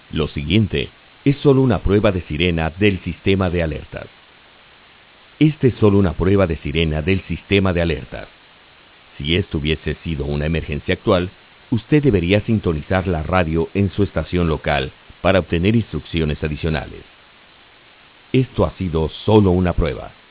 Test Message